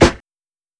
Snare (Is There Any Love).wav